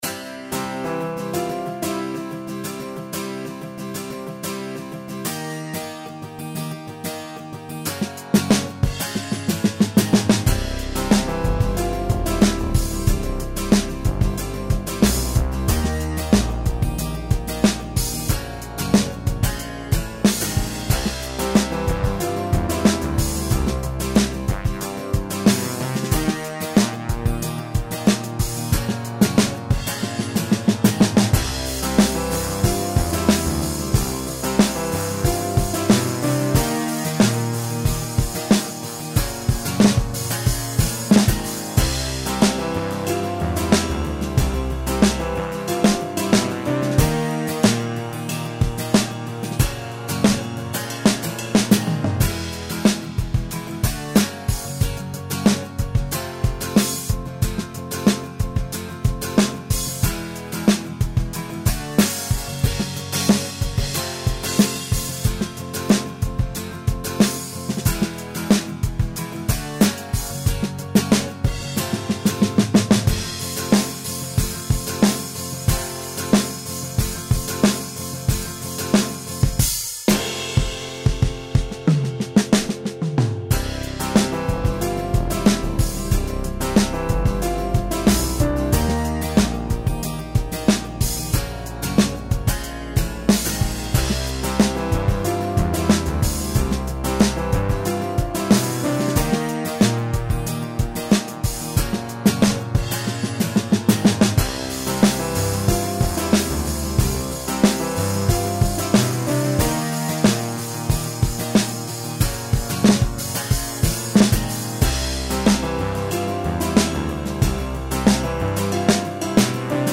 demo_retro_1 Категория: Написание музыки
просто набросочек, тестировал новый VST ночью :)